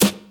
Trebly Snare Sample G Key 300.wav
Royality free snare one shot tuned to the G note. Loudest frequency: 3599Hz
trebly-snare-sample-g-key-300-5bN.mp3